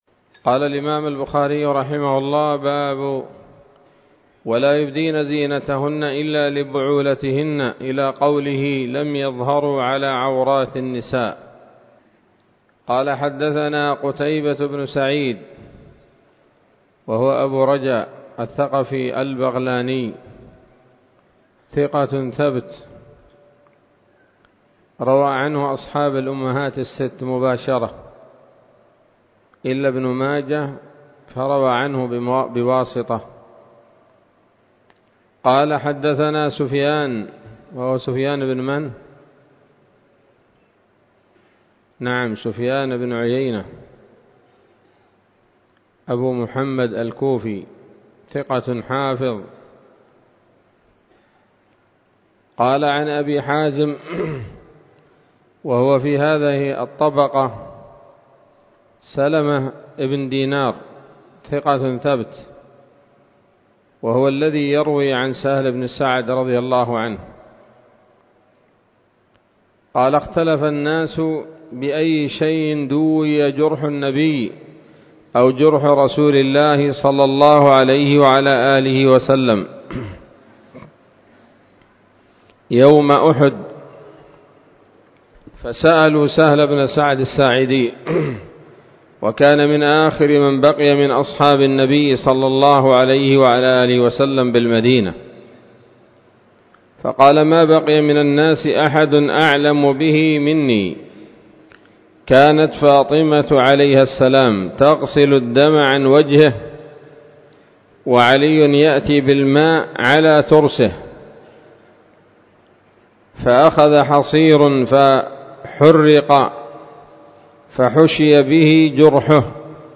الدرس الخامس والتسعون من كتاب النكاح من صحيح الإمام البخاري